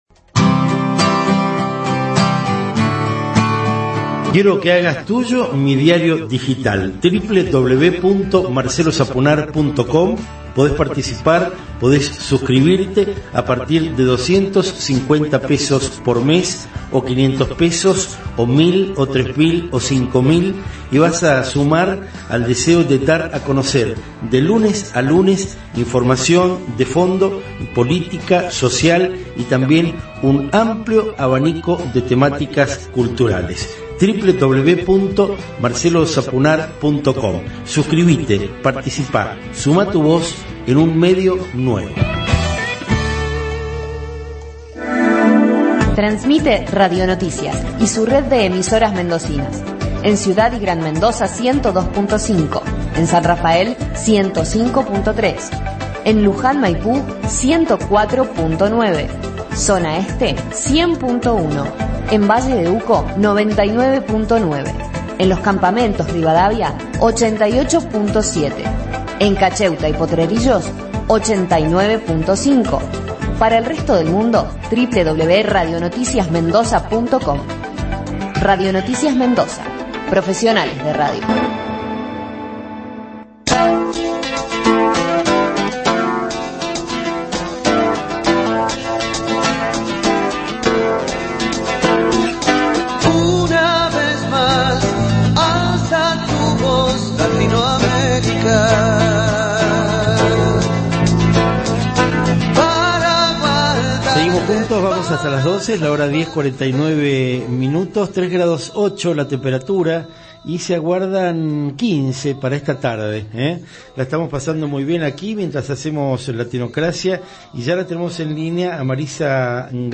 Entrevista: Marisa Garnica, Diputada Provincial, Latinocracia, 4 de julio de 2022, por Radio Noticias FM 102,5